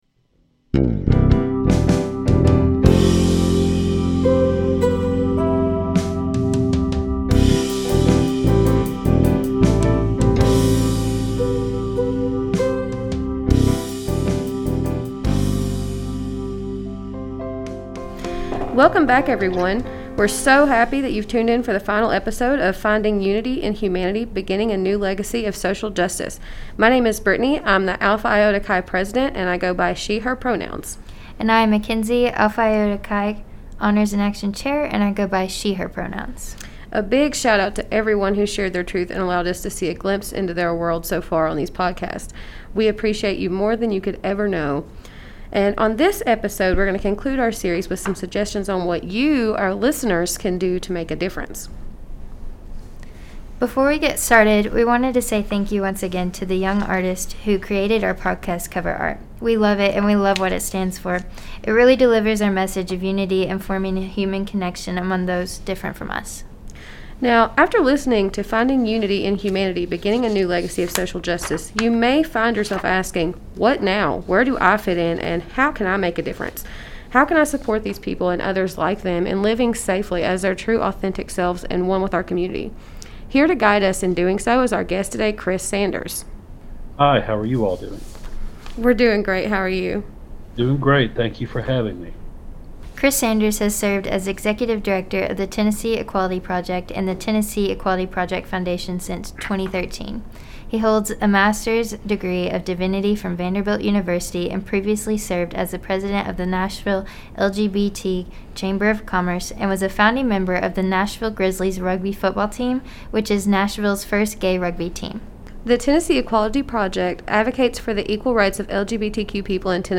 In this episode, we interview